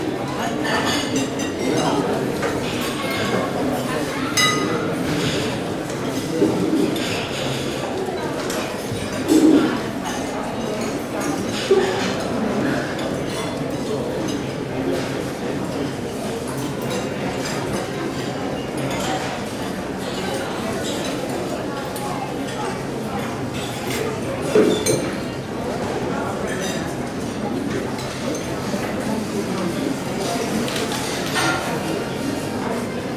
Indoor atmospheres 2